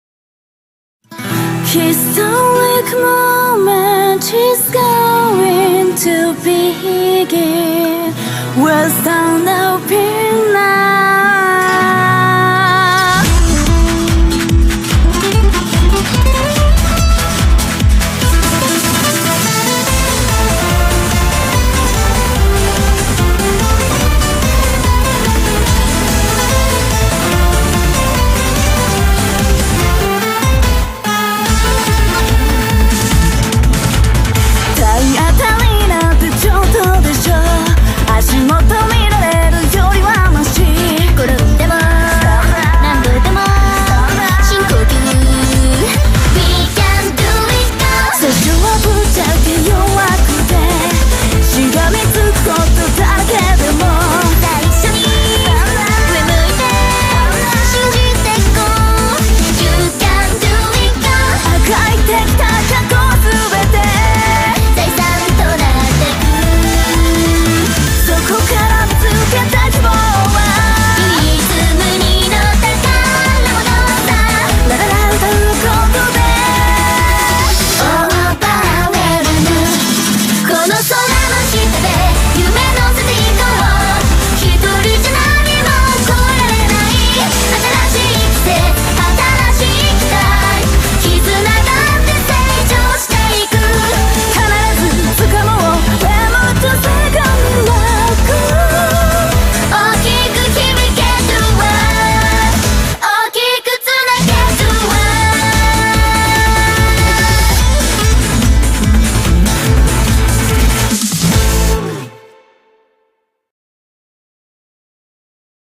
BPM145-145
Audio QualityPerfect (Low Quality)